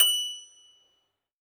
53s-pno23-F5.aif